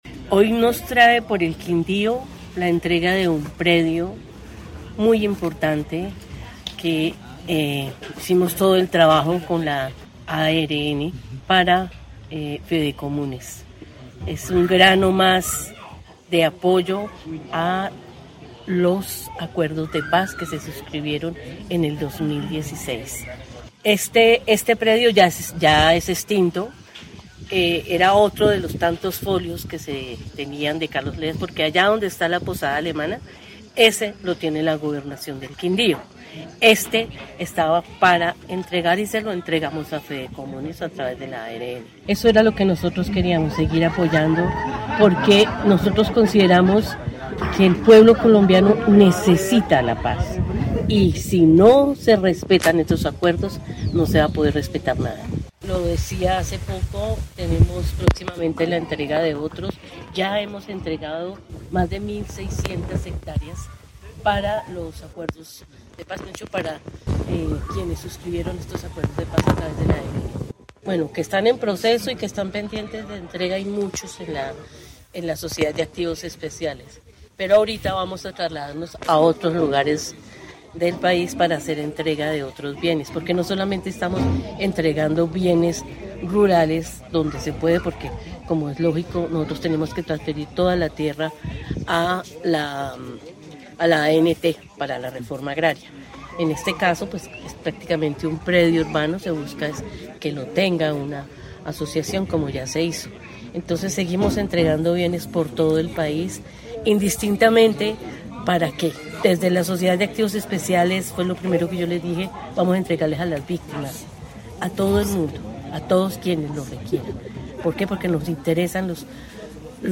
Presidenta de la SAE, Amelia Pérez